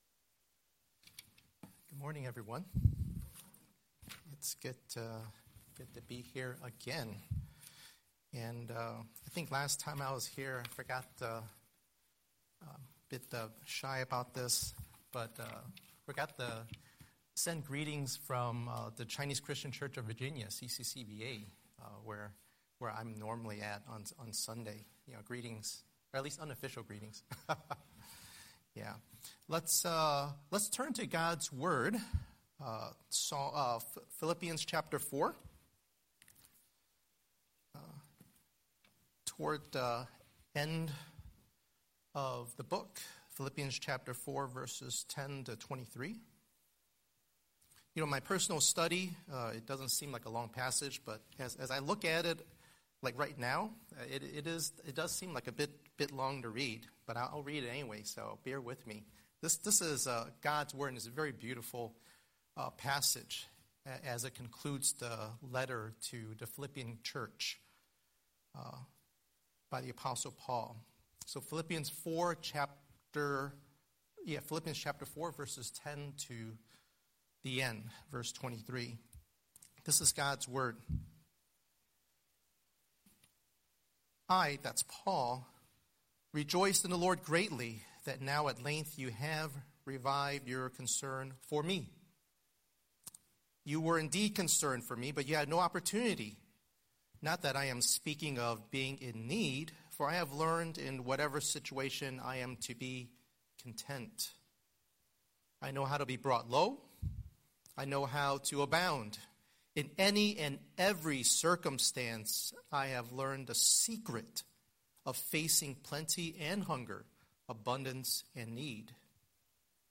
Scripture: Philippians 4:10–23 Series: Sunday Sermon